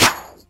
Snare (3).wav